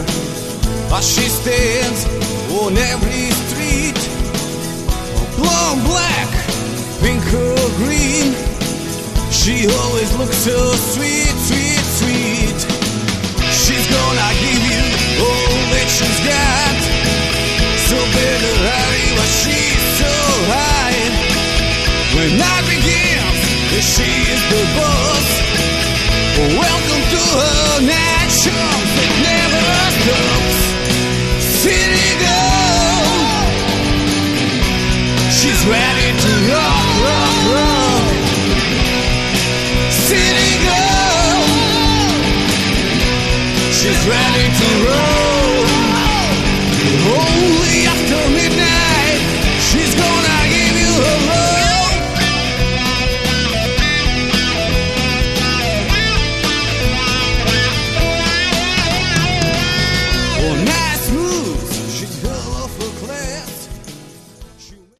Category: Modern Hard Rock
lead vocals, guitars
lead guitar, backing vocals
bass
drums, percussion